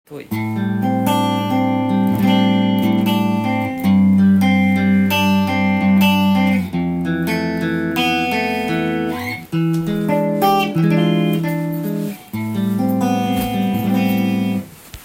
試しに弾いてみました
ディマジオのハムバッカーが載っているので
クリーントーンは、艶のある太い感じです。
シングルコイルでは、出せないハイパワーサウンドが出せます。